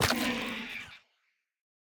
Minecraft Version Minecraft Version 25w18a Latest Release | Latest Snapshot 25w18a / assets / minecraft / sounds / block / sculk_sensor / break1.ogg Compare With Compare With Latest Release | Latest Snapshot
break1.ogg